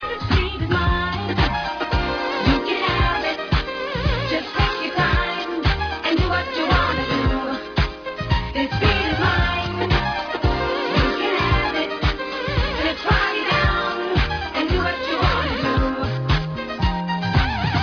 a Soulful Funky-Disco track